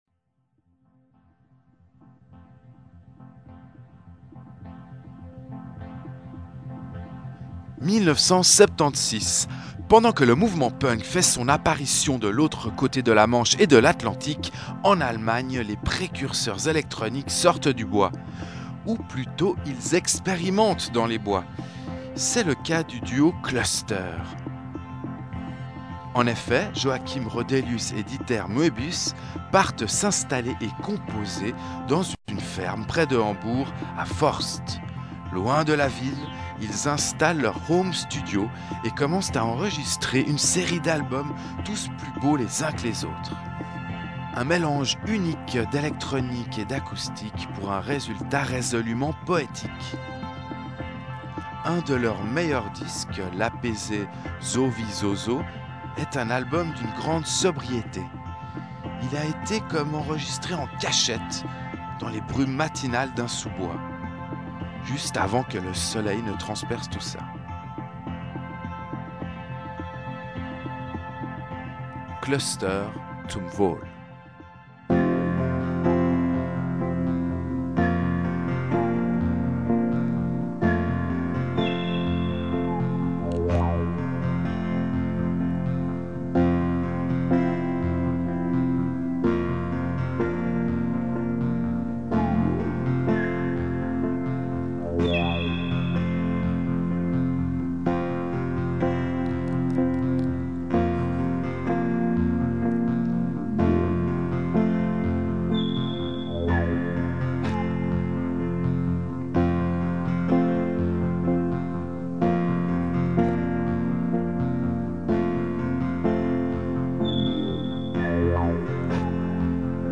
Chronique hebdomadaire